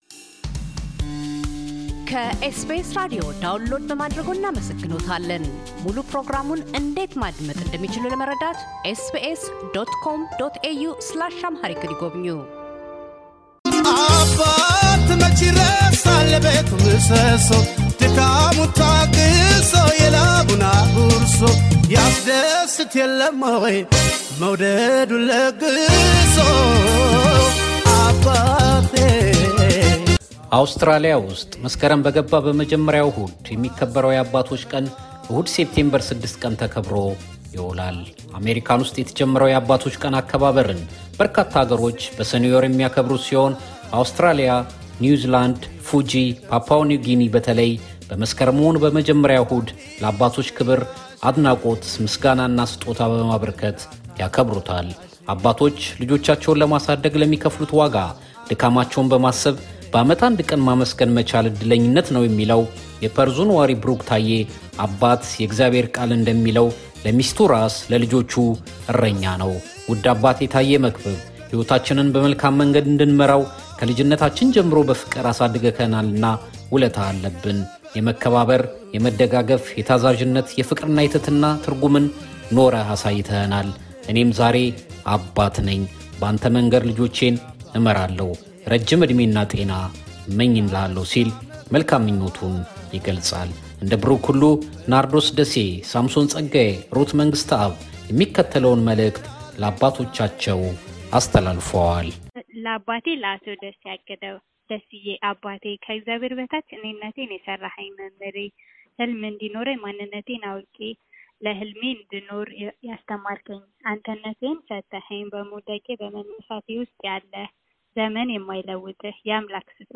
ታዳጊ ልጆችም ለአባቶቻቸው የ ‘እንኳን አደረሰህ’ መልዕክቶችን ያስተላልፋሉ።